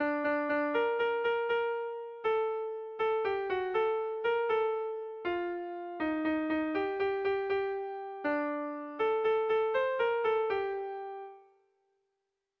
Sentimenduzkoa
Lauko ertaina (hg) / Bi puntuko ertaina (ip)
AB